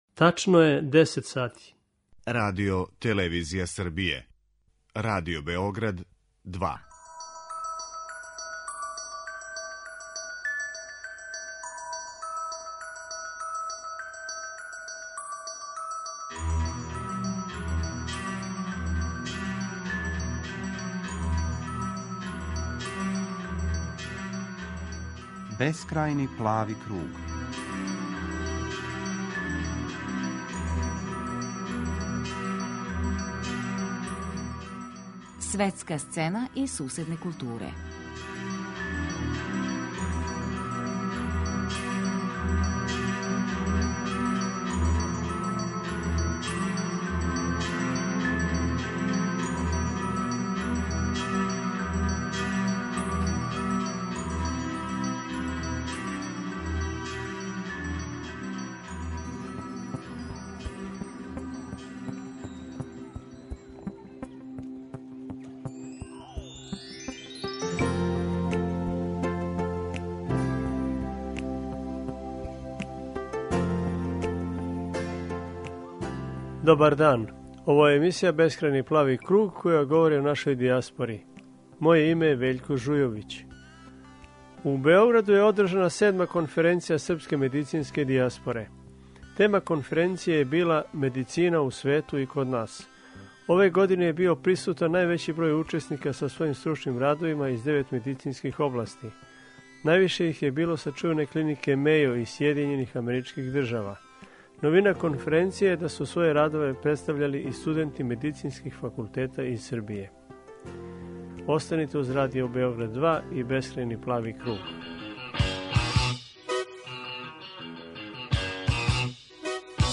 Данас ћемо чути и упознати неке од лекара учесника конференције који живе и раде у дијаспори и сазнати која су њихова искуства и како да се oнa пренесу у отаџбину.